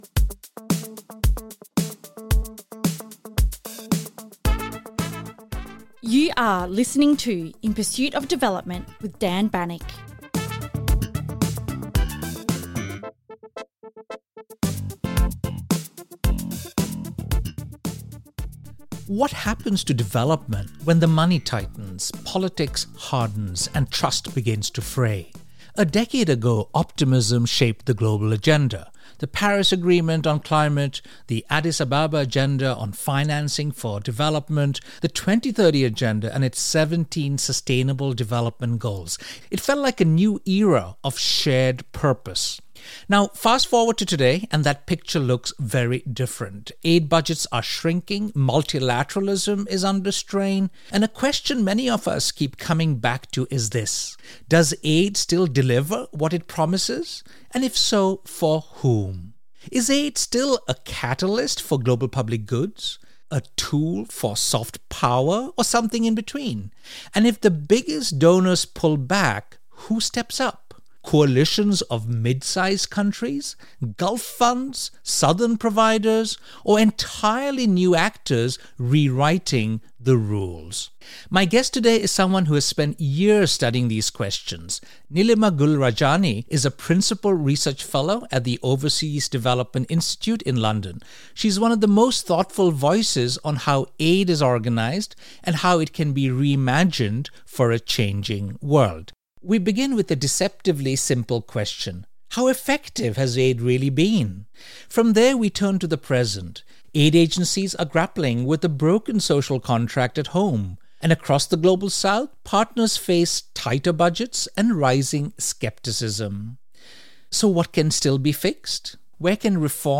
Listen to a series of interviews with two medical retina specialists and a biomarker expert who will provide their insights into the pathophysiology of diabetic macular oedema (DMO), including the inflammatory mechanisms of disease and how they may impact treatment.